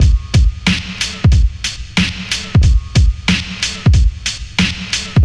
• 92 Bpm 00's Drum Loop G# Key.wav
Free drum loop sample - kick tuned to the G# note. Loudest frequency: 1161Hz
92-bpm-00s-drum-loop-g-sharp-key-7OR.wav